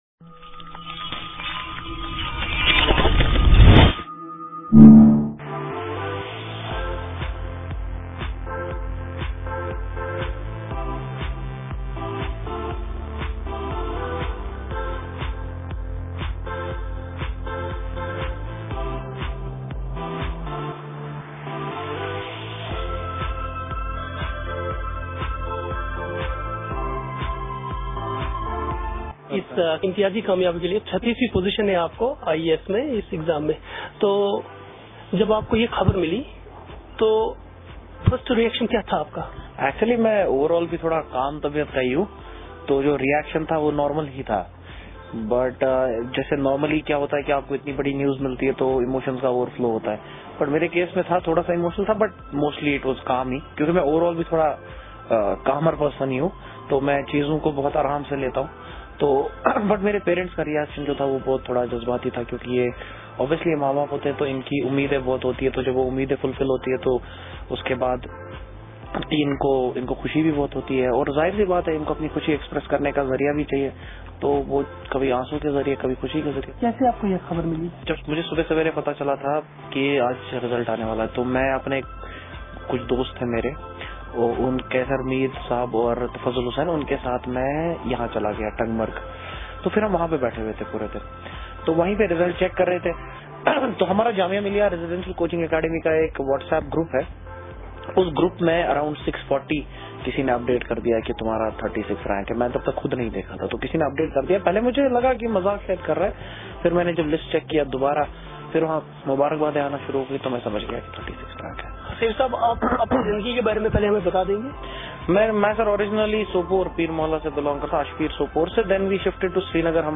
ایک خصوصی انٹریو